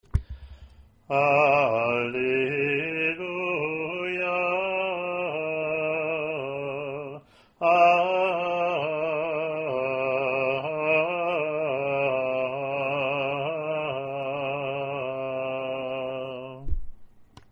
Alleluia Acclamation